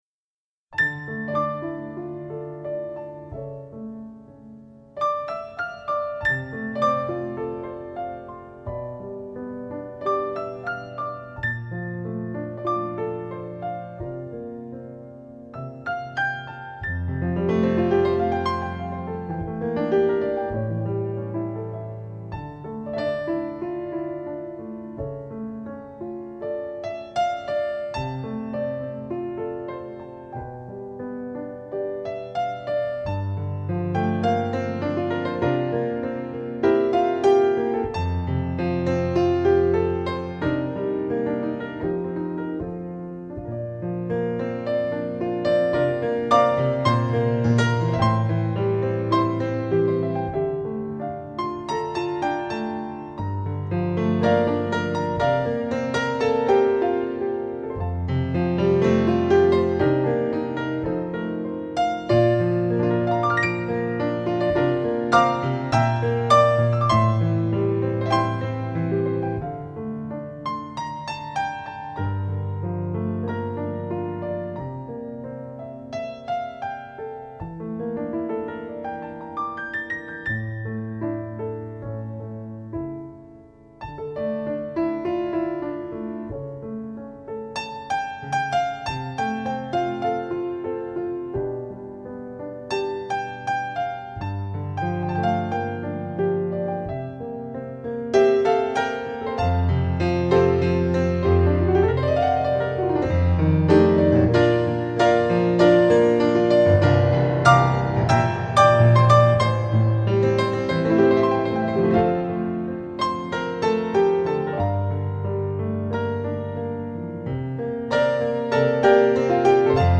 属于老牌经典的爵士乐曲了。